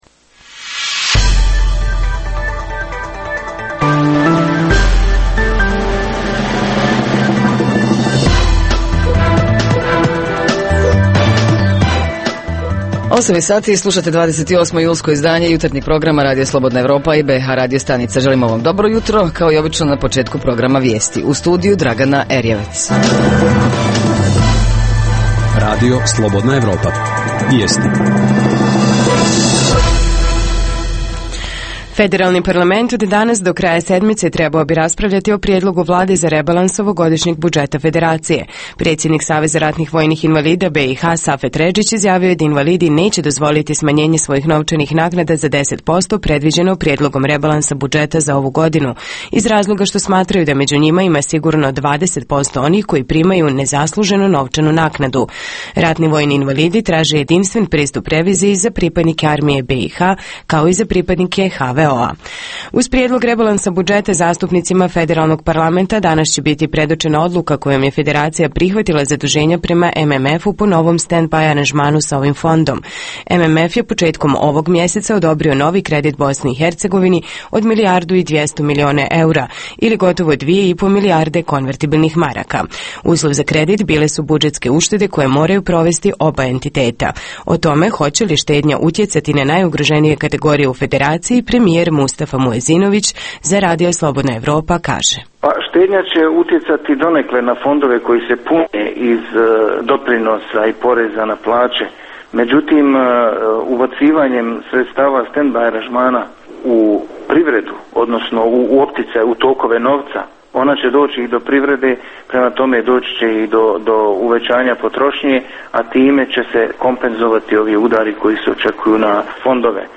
Jutarnji program za BiH koji se emituje uživo - istražujemo koliko nam je razvijena informatička pismenost. Reporteri iz cijele BiH javljaju o najaktuelnijim događajima u njihovim sredinama.
Redovni sadržaji jutarnjeg programa za BiH su i vijesti i muzika.